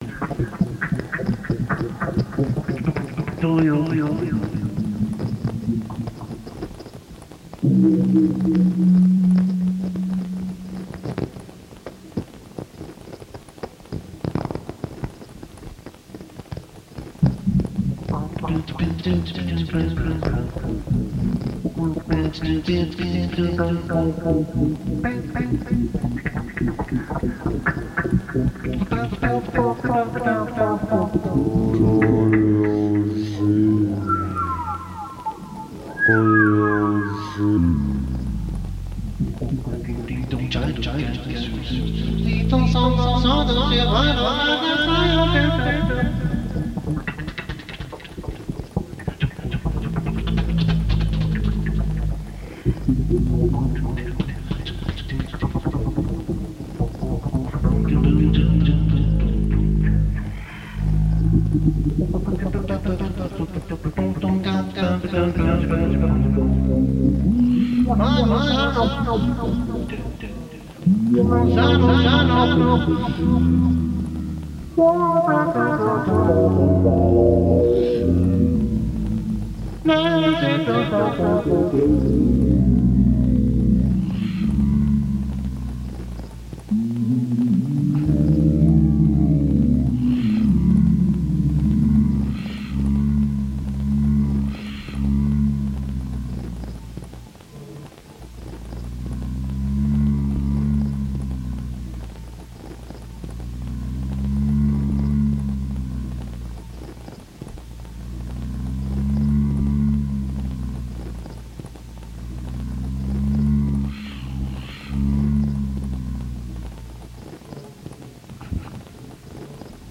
Recorded live at Barbes, Park Slope, Brooklyn.
alto clarinet, fx, vocal
peck horn, percussion, fx
Stereo (722 / Pro Tools)